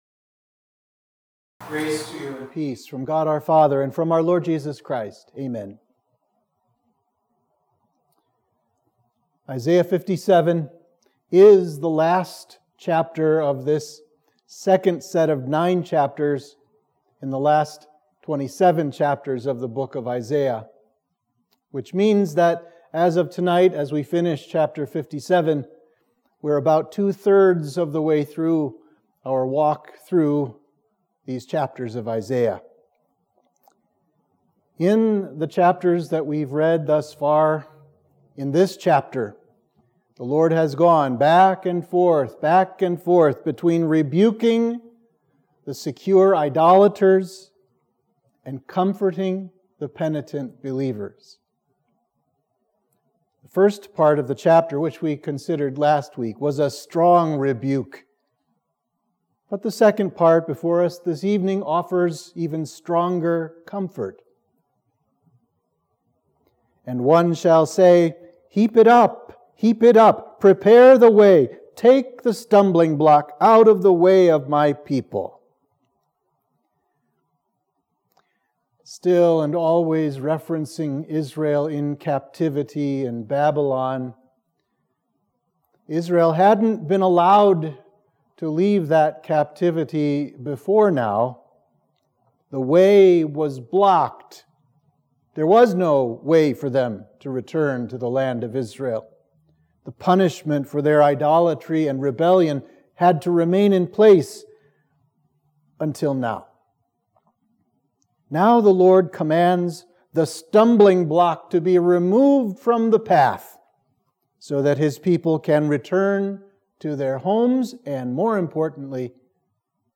Sermon for Midweek of Trinity 14